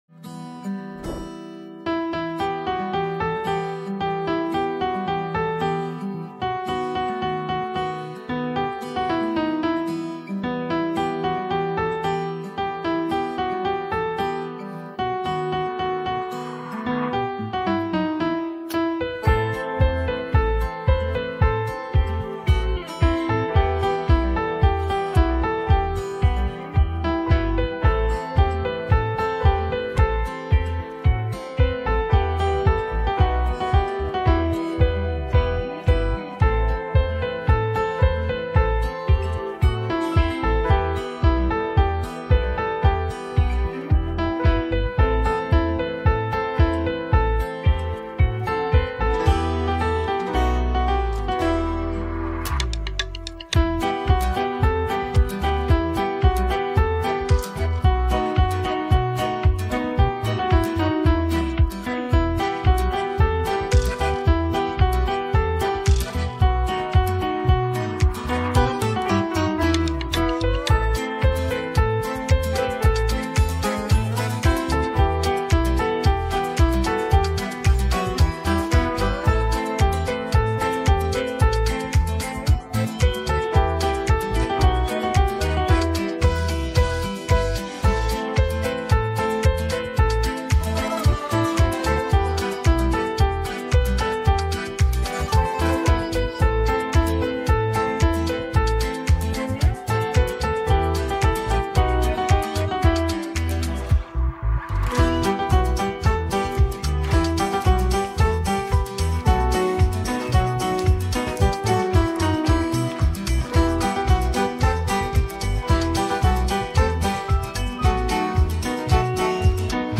מאוד לא ברור הקלטה שקטה מאוד…